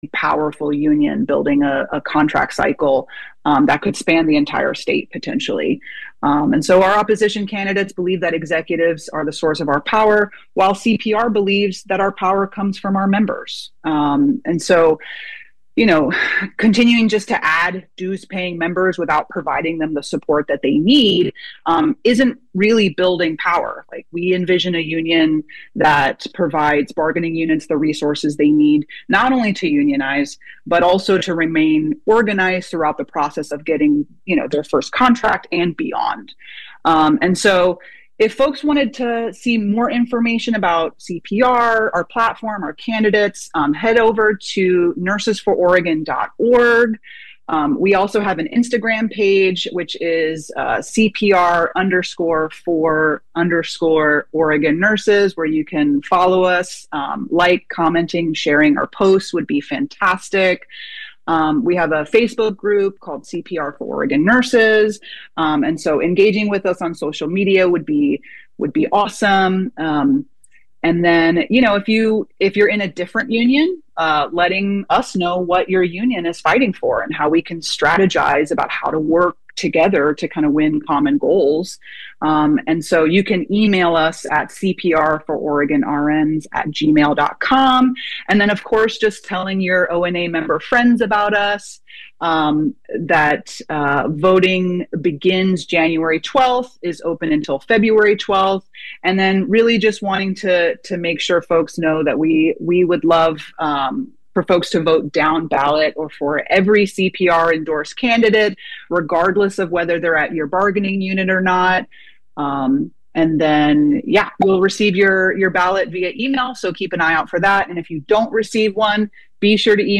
We’ll hear messages and shout outs from the Family Preservation Project alumni to the women and mothers in Coffee Creek Correctional Facility.